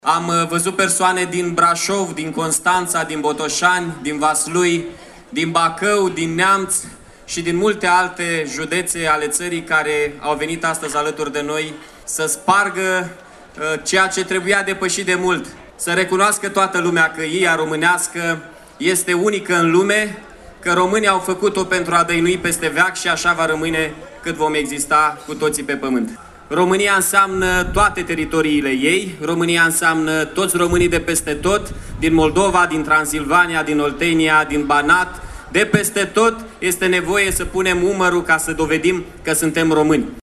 Piața Palatului și pietonalul Ștefan cel Mare din Iași găzduiesc, astăzi, Festivalul RomânIA autentică.